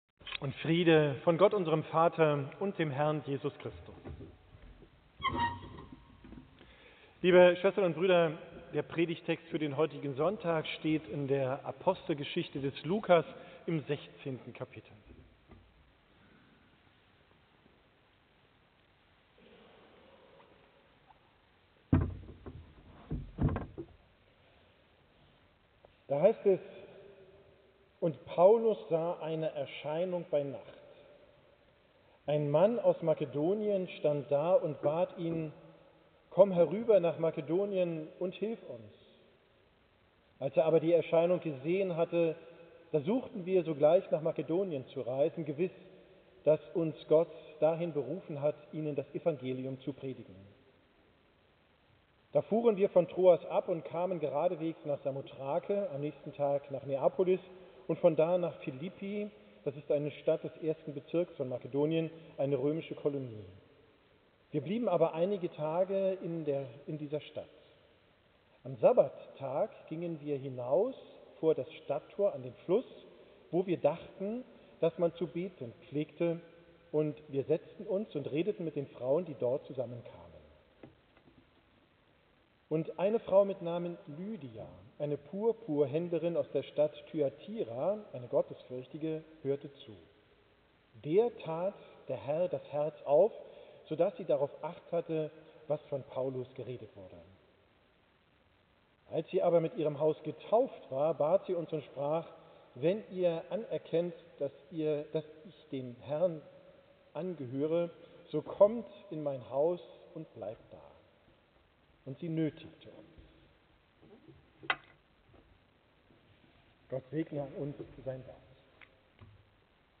Prerdigt vom Sonntag Sexagesma, 23.